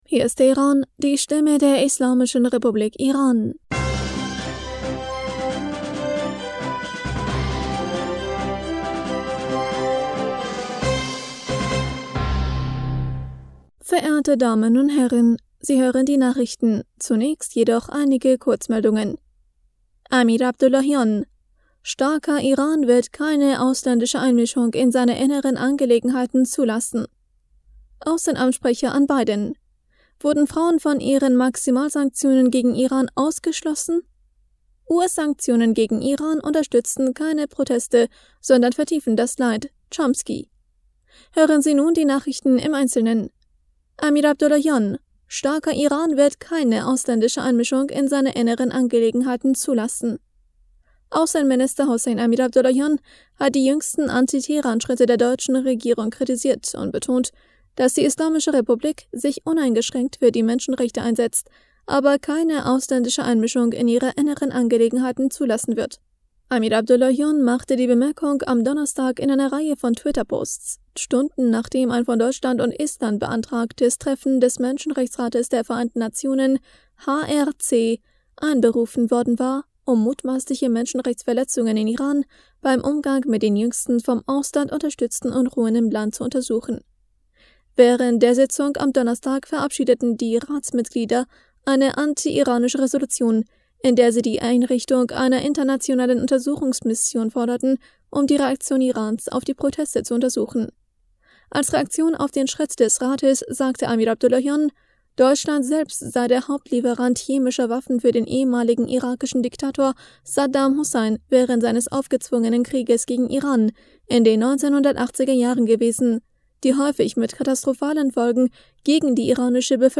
Nachrichten vom 25. November 2022
Die Nachrichten von Freitag, dem 25. November 2022